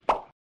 pop.mp3